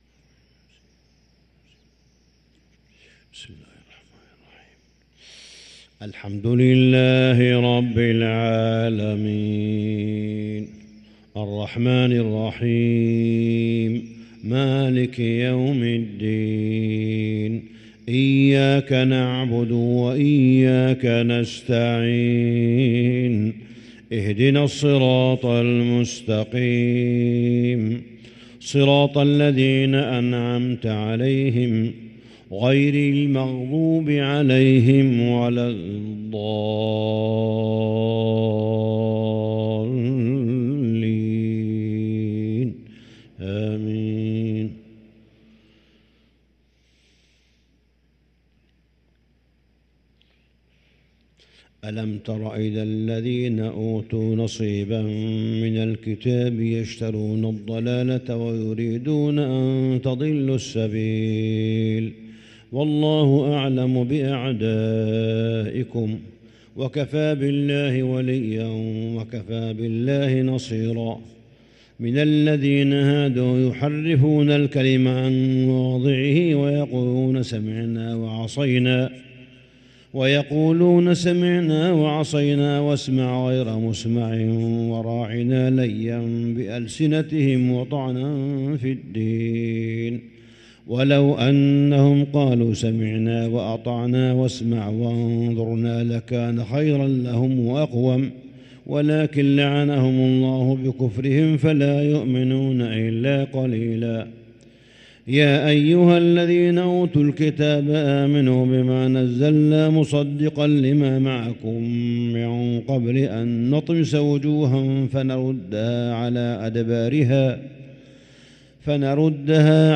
صلاة الفجر للقارئ صالح بن حميد 5 رمضان 1444 هـ
تِلَاوَات الْحَرَمَيْن .